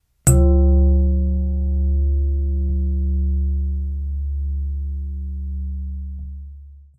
wouldn't have thought that my bedside lamp would sound like that.